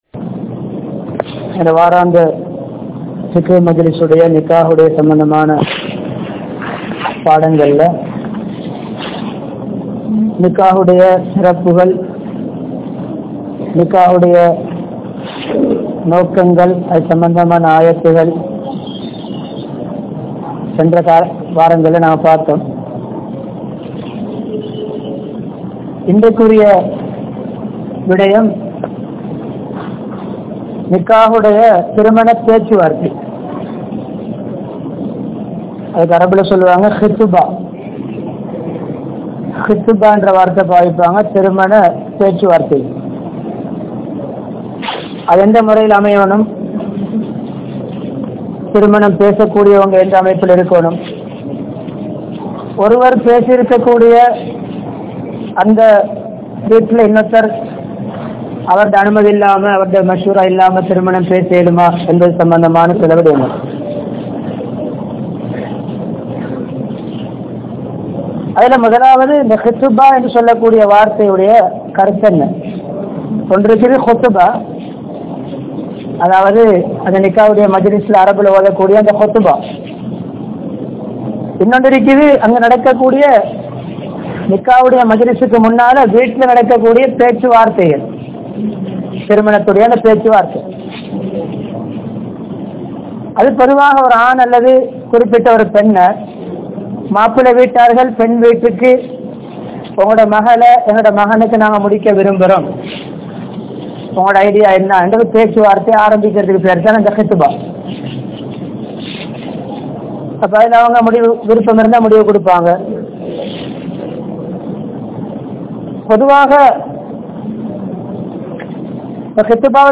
Kudumba Vaalkai (குடும்ப வாழ்க்கை)Fiqh Lesson 02 | Audio Bayans | All Ceylon Muslim Youth Community | Addalaichenai
Colombo 14, Layards Broadway, Jamiul Falah Jumua Masjidh